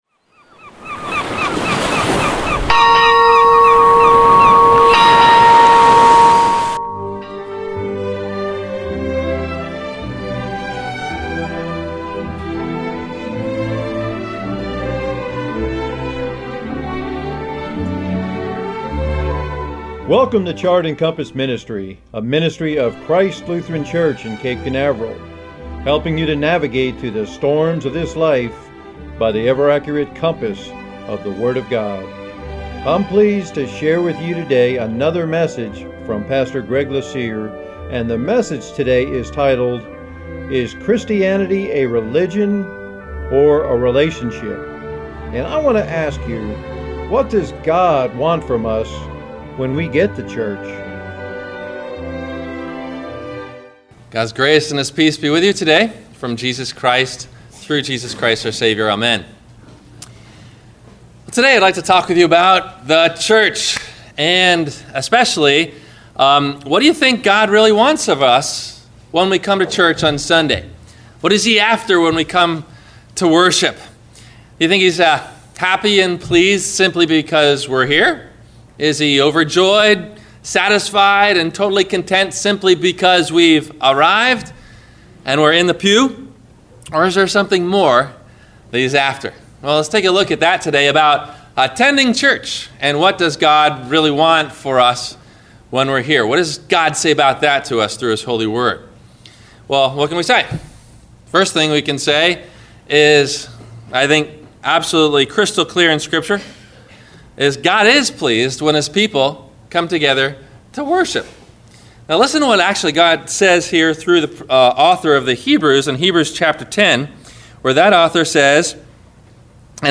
Is Christianity a Religion or a Relationship? – WMIE Radio Sermon – June 26 2017